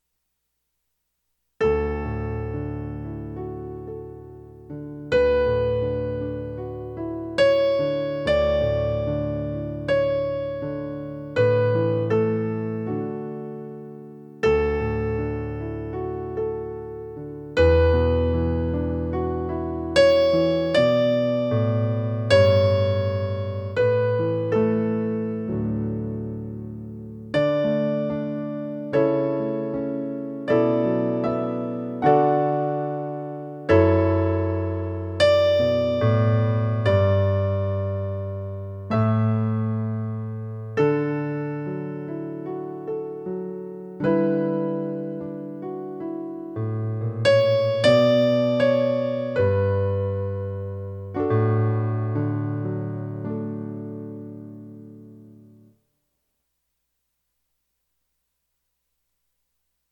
ChristBeforeUs_Descant.mp3